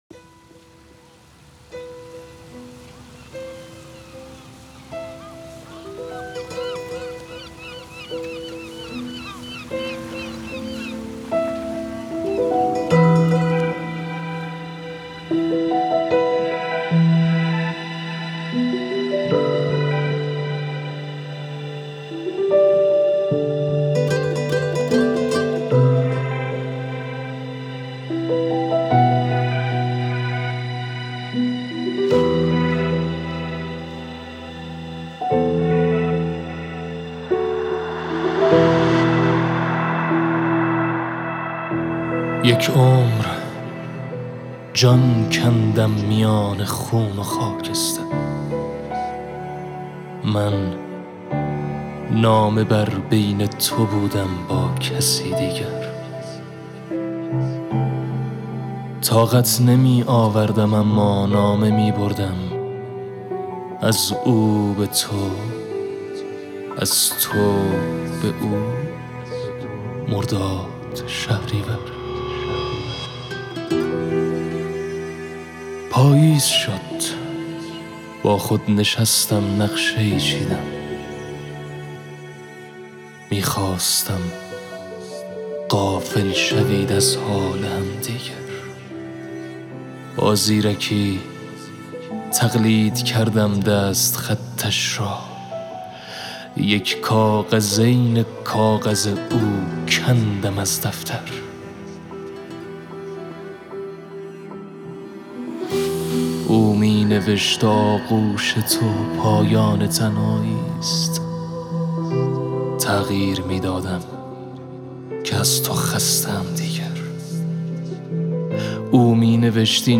دکلمه نامه بر